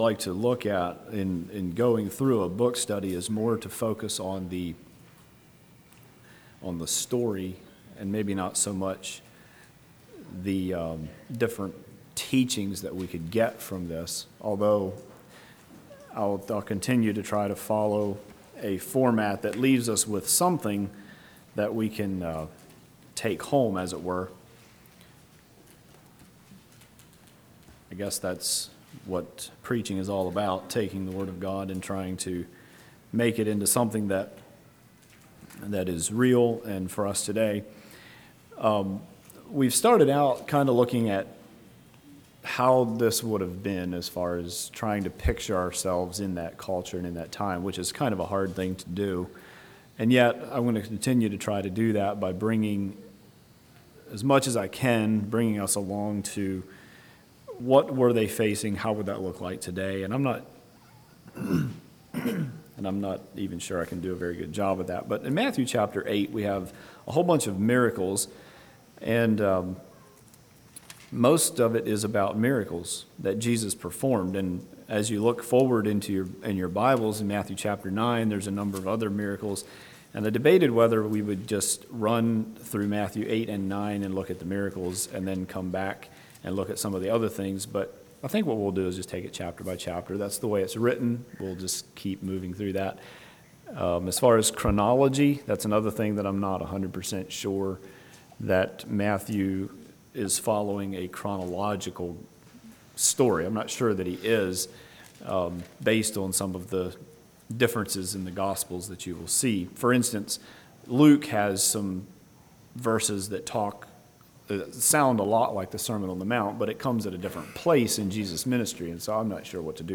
Play Now Download to Device Matthew 8 Congregation: Darbun Speaker